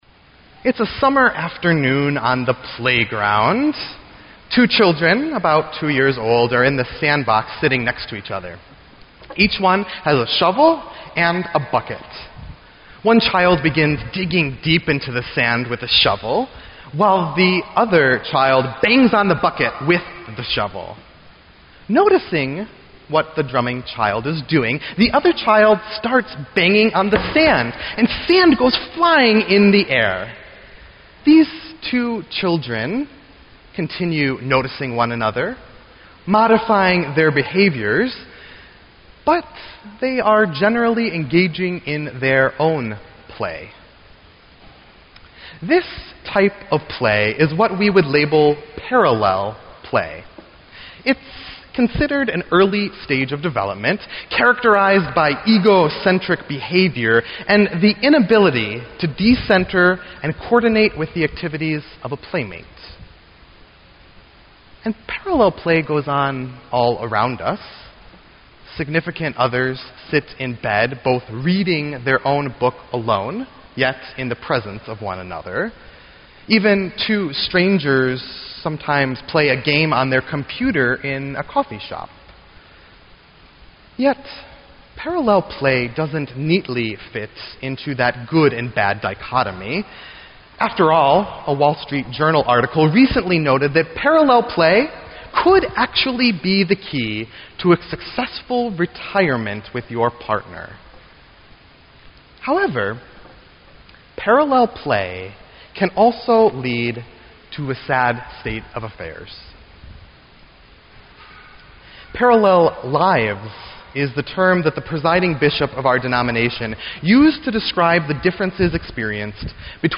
Sermon_9_6_15.mp3